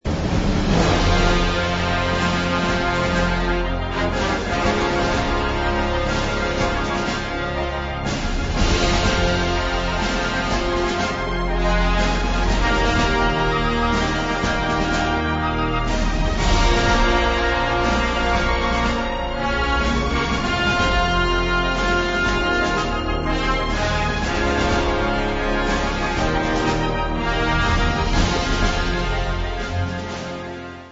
et ægte kraftværk af et symfonisk actionscore
er især domineret af blæsersektionerne
et fuldstændig over-the-top heroisk actionnummer